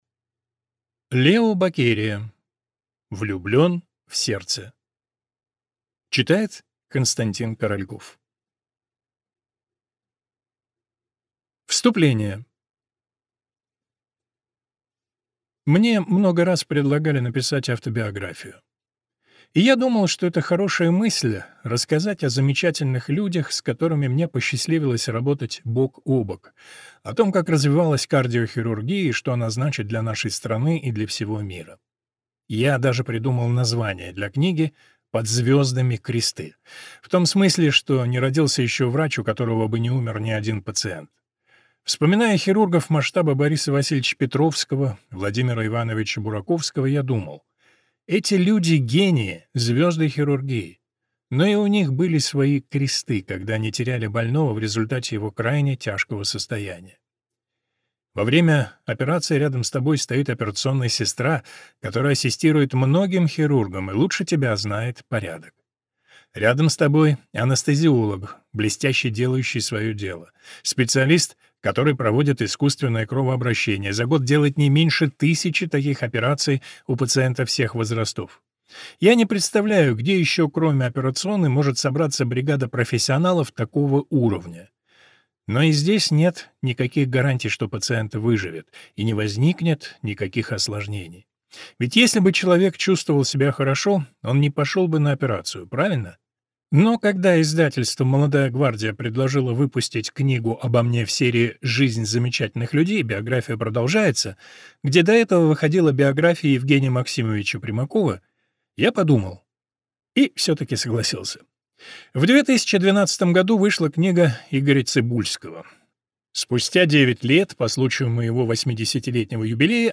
Аудиокнига Лео Бокерия: «Влюблен в сердце». Истории от первого лица | Библиотека аудиокниг